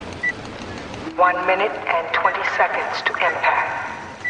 • Woman over PA - one minute and 20 seconds to impact.ogg
[woman-over-pa]-one-minute-and-20-seconds-to-impact_lwz.wav